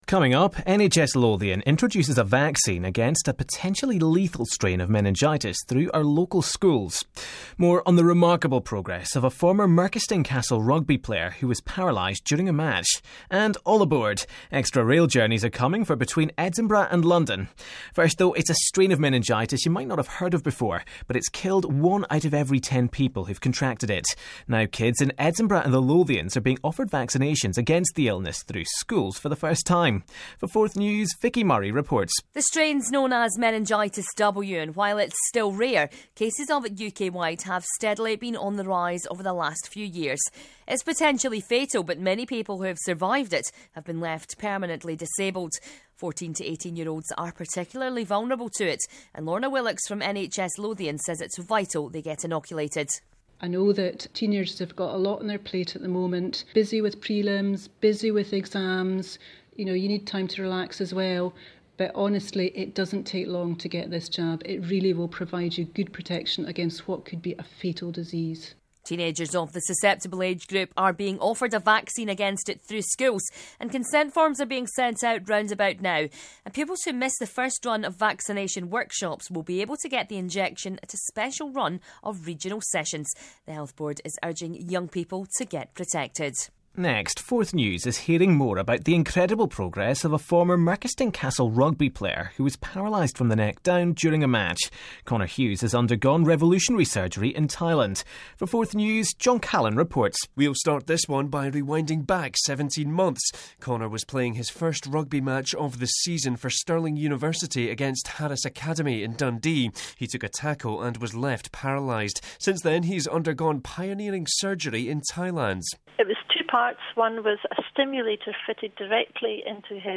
The full round-up of latest from Forth News at 1pm.